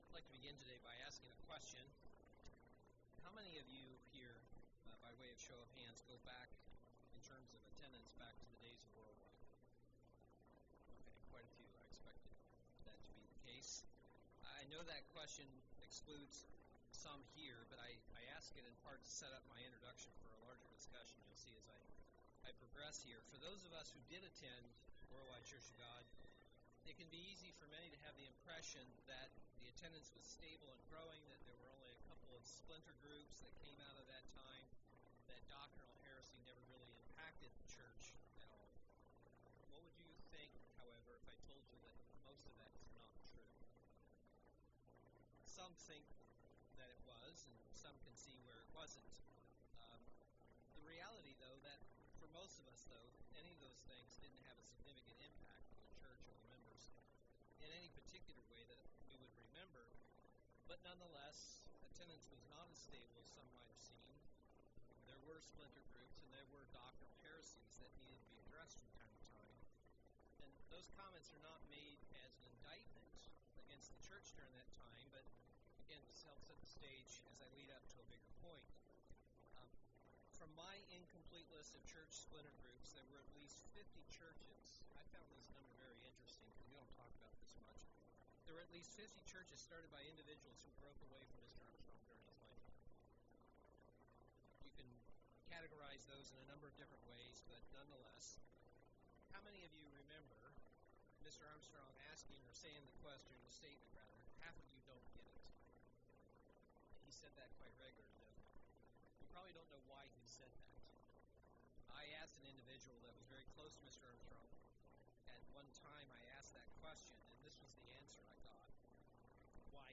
Given in Milwaukee, WI
UCG Sermon lessons Studying the bible?